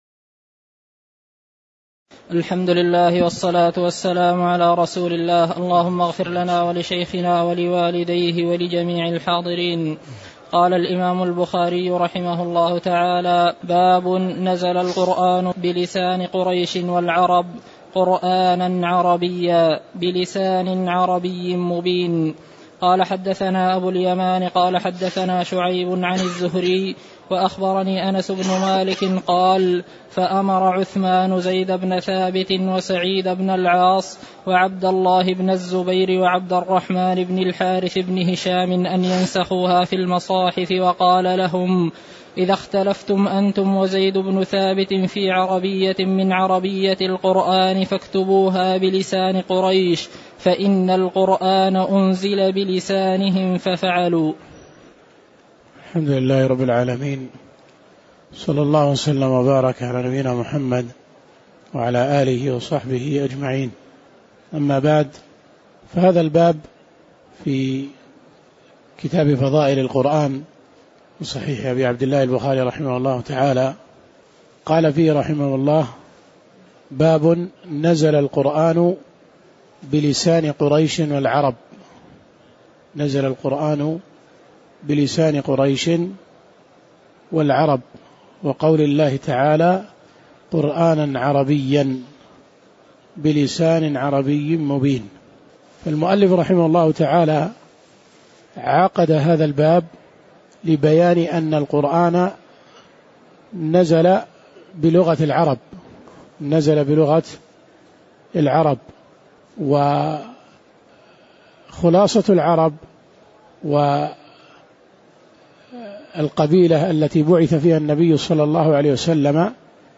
تاريخ النشر ١٣ رمضان ١٤٣٩ هـ المكان: المسجد النبوي الشيخ